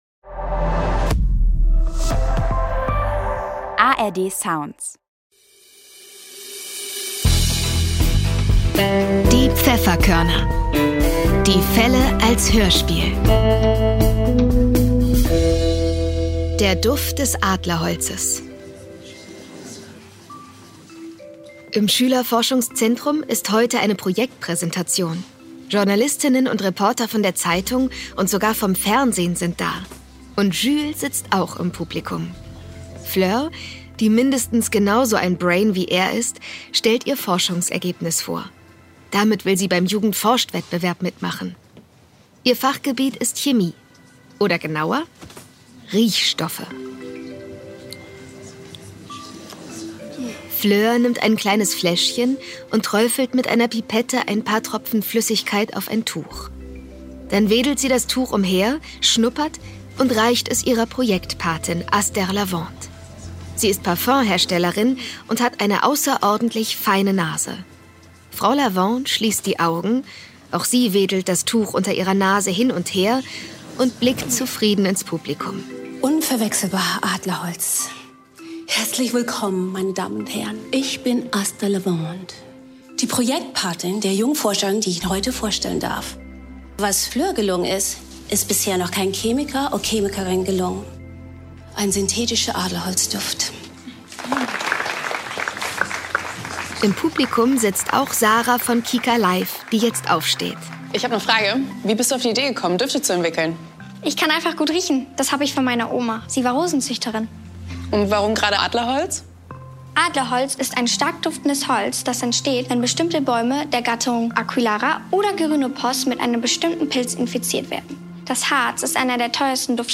Der Duft des Adlerholzes (18/21) ~ Die Pfefferkörner - Die Fälle als Hörspiel Podcast